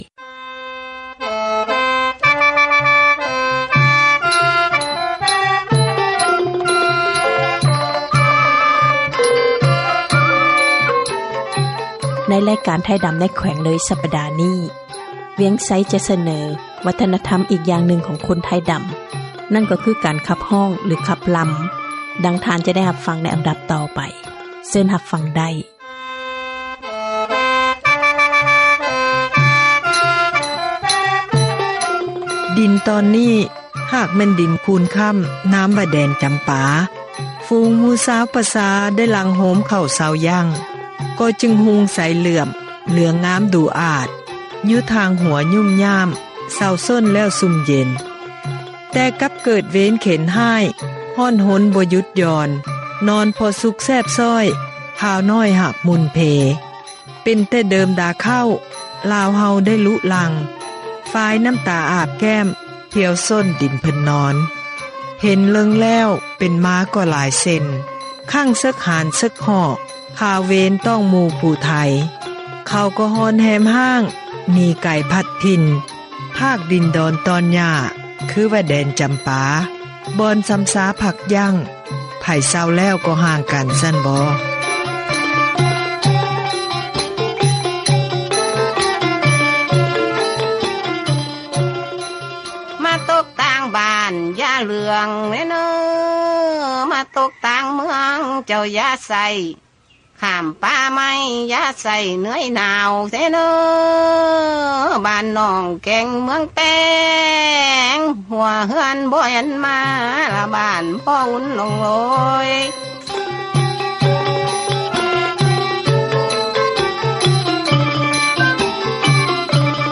ຣາຍການ ໄທດໍາ ໃນ ແຂວງເລີຍ ສັປດາ ນີ້ ຈະຂໍສເນີ ວັທນະທັມ ອີກຢ່າງນຶ່ງ ຂອງ ຄົນໄທດໍາ ນັ້ນກໍ່ຄື ການຂັບຮ້ອງ ຂັບລໍາ. ດັ່ງທ່ານ ຈະໄດ້ຟັງ ໃນອັນດັບ ຕໍ່ໄປ...